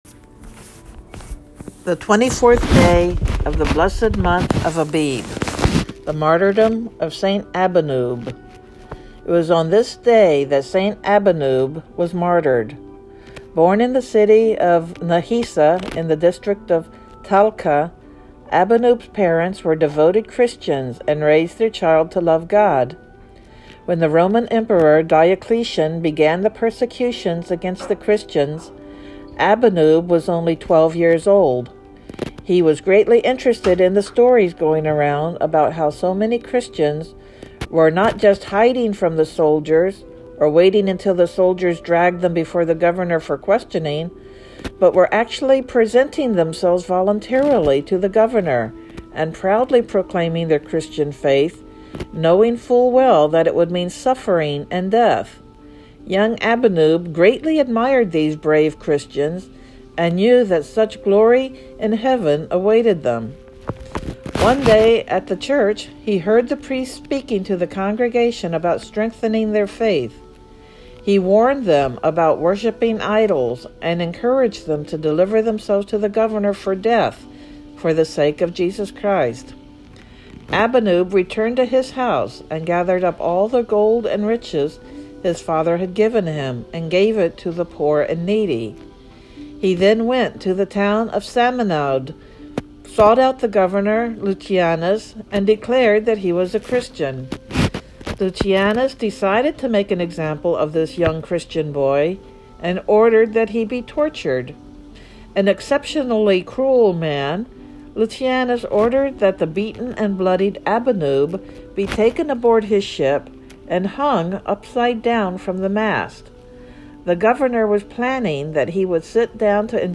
Synaxarium readings for the 24th day of the month of Abib